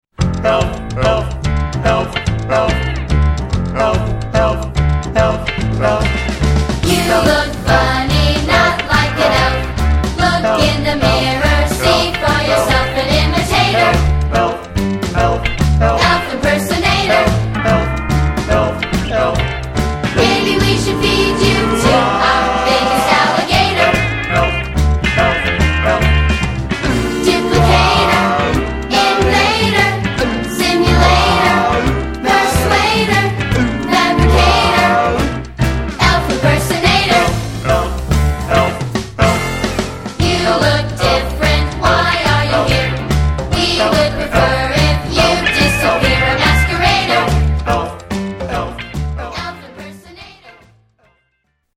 A Rockin’ Holiday Fantasy For Young Voices